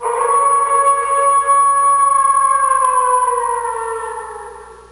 آنالیز زوزه ی گرگ: روش انجام کار
در این طیف، مشخص است که گرگ در طول زمان حدود ۴. ۹۳۲ ثانیه، زوزه ای با ساختار “Flat”، شروعی بالا رونده و انتهایی پایین رونده سر داده است:
در شروع این فایل صوتی، صدایی با طول زمان حدود ۰. ۲۲۷ ثانیه را می شنویم که بر اساس تحلیل طیف صدا، فاصله ای است بینA#4 و B4: